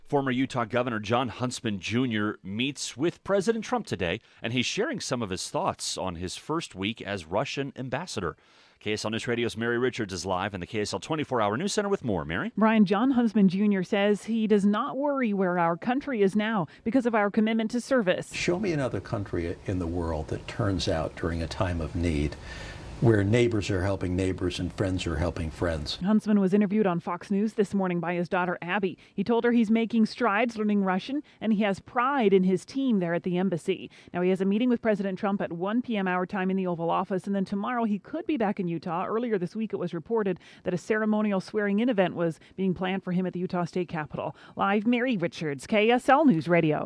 The former Utah governor spoke to his daughter Abby on Fox News about his new position in Russia.